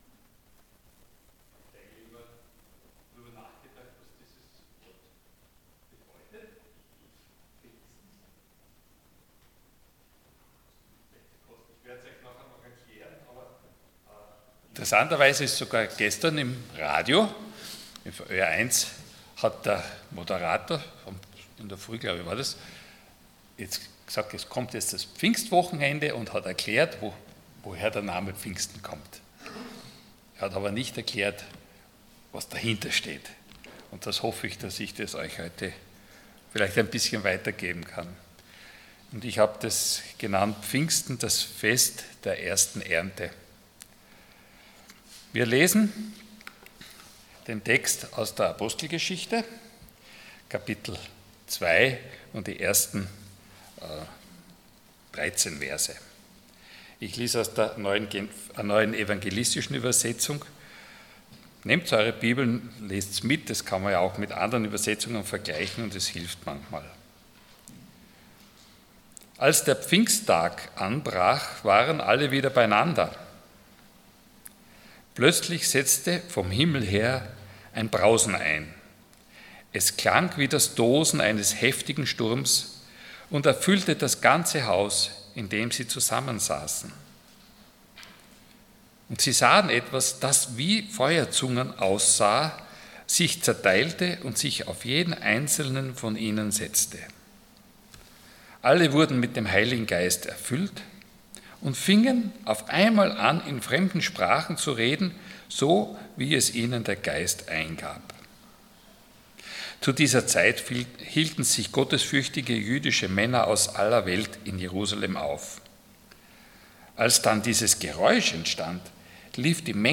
Passage: Apostelgeschichte 2,1-13 Dienstart: Sonntag Morgen